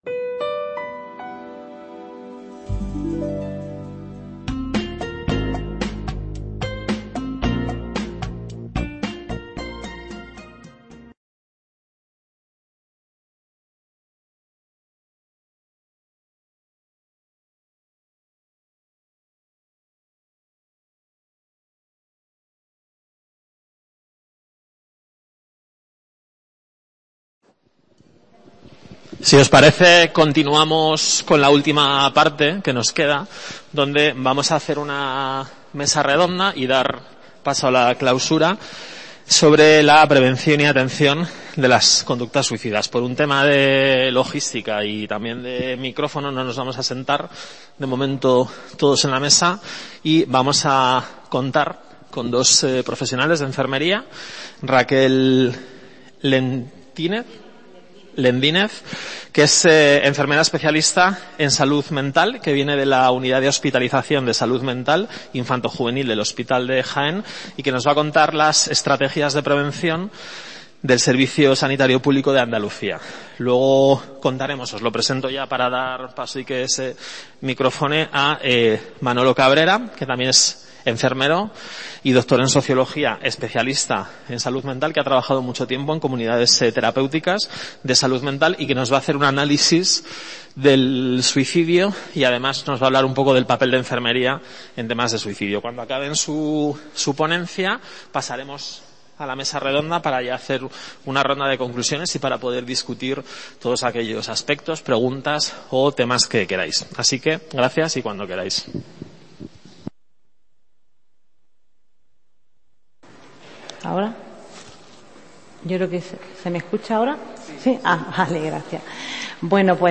Mesa redonda
Incluye la clausura del curso STOP SUICIDIO: UNA OPORTUNIDAD PARA VIVIR que se celebra en Alcalá la Real (5 a 7 de julio de 2021) en los Cursos de Verano de la UNED.